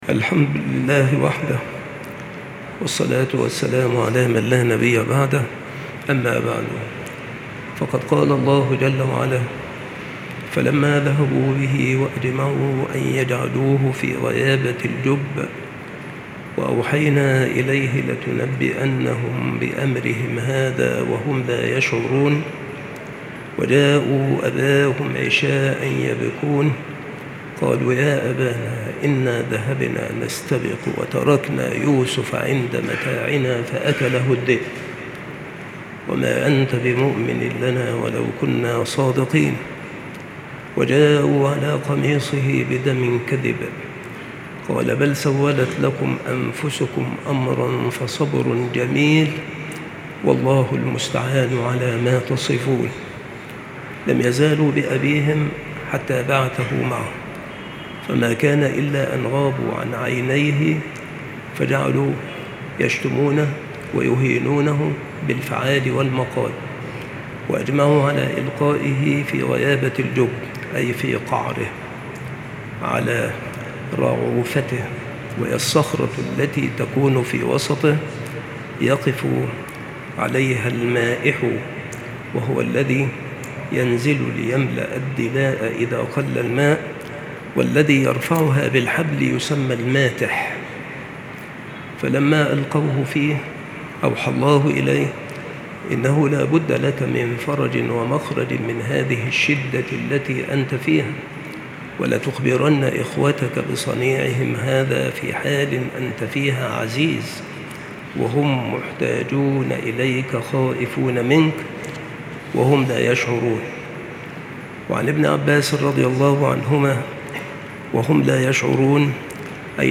بالمسجد الشرقي - سبك الأحد - أشمون - محافظة المنوفية - مصر